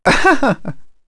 Fluss-Vox-Laugh.wav